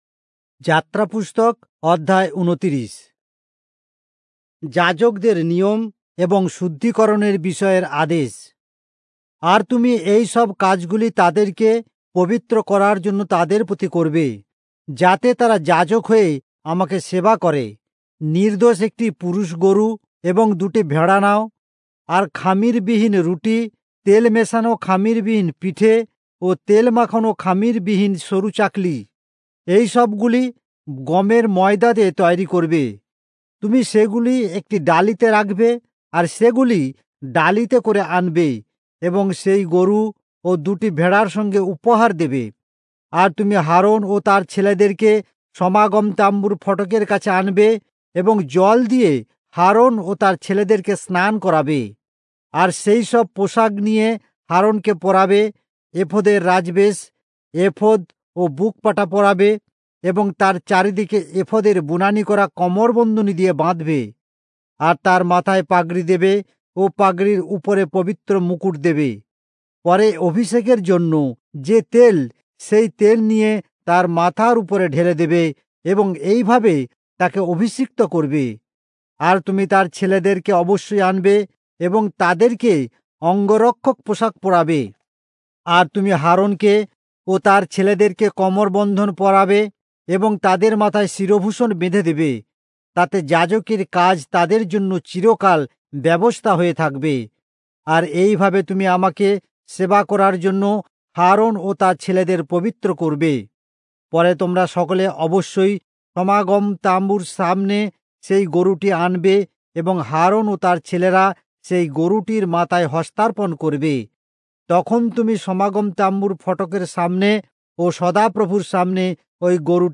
Bengali Audio Bible - Exodus 29 in Irvbn bible version